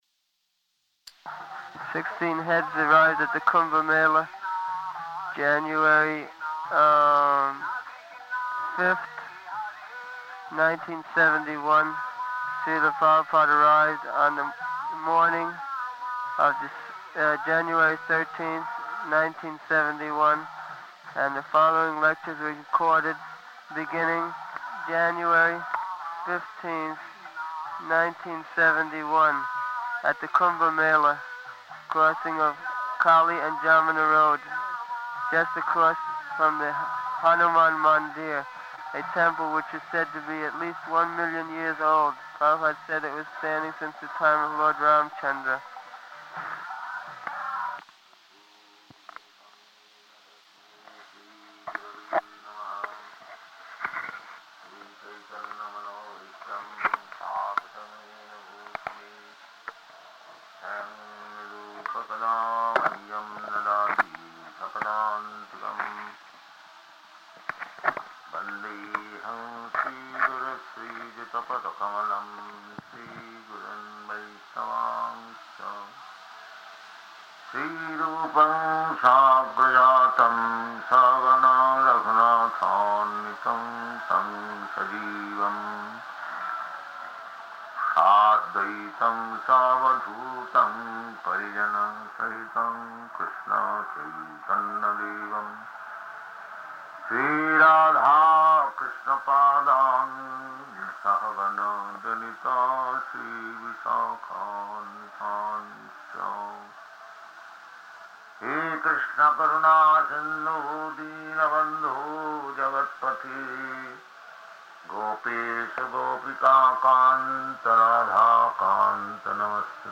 Śrīla Prabhupāda arrived on the morning of January 13th, 1971, and the following lectures were recorded beginning January 15th, 1971, at the Kumbha-melā, crossing of Kali and Yamunā roads, just across from the Hanumān Mandira, a temple which is said to be at least one million years old.